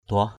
/d̪ʊah/